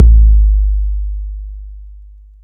Kick 888.wav